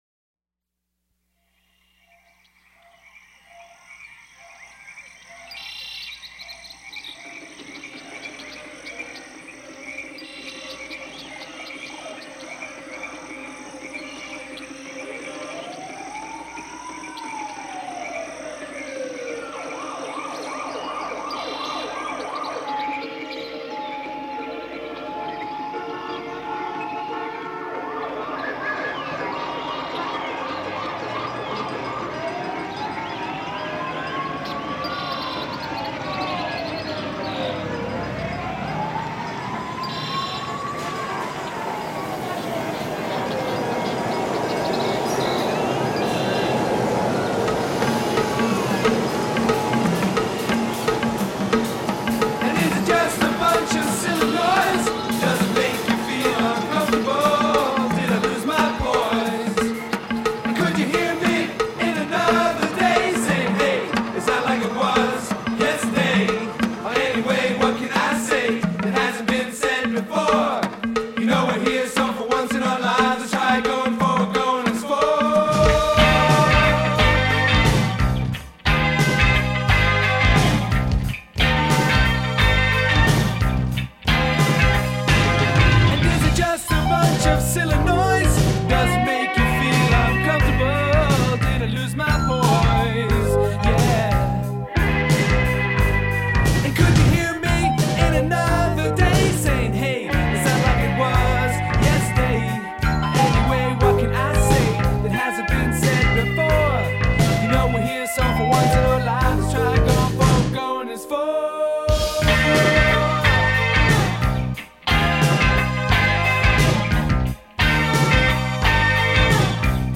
He did a full blown (back then) 24-track version. Fat 80’s sound.
guitars
keyboards